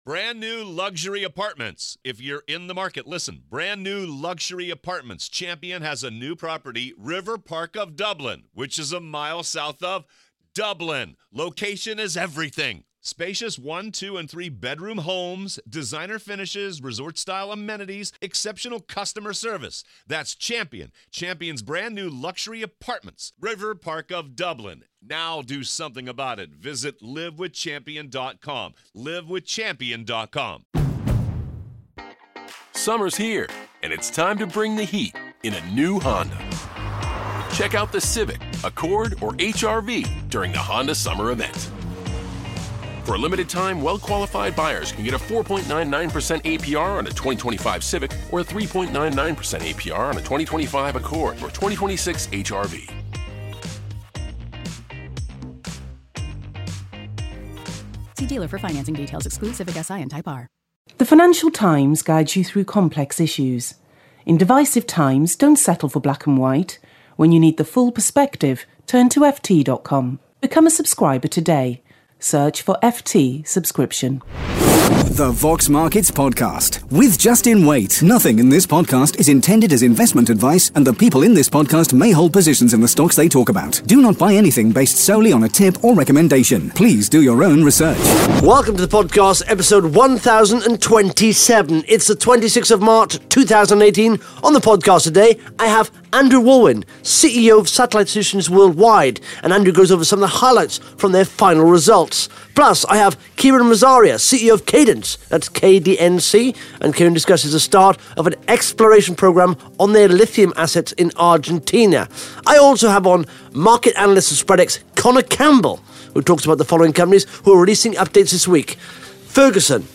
(Interview starts at 1 minute 3 seconds)